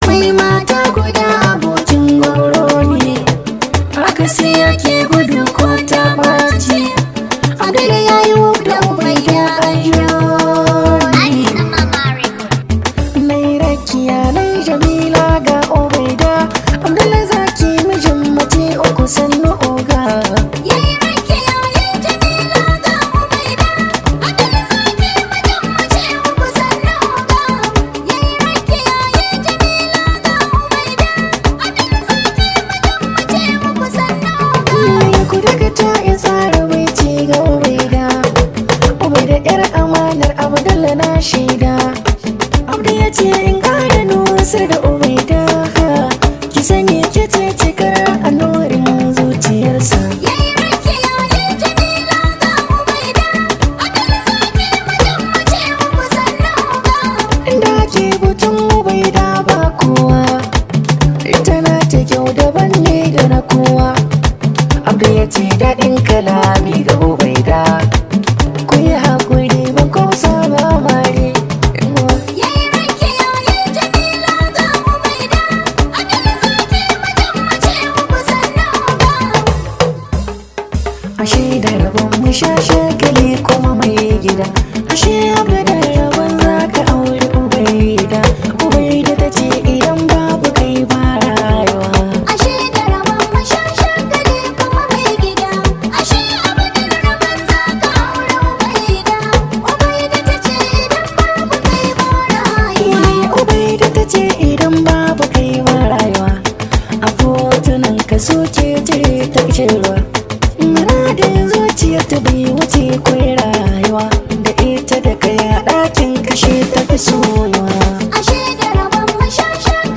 much appreciated hausa song known as
high vibe hausa song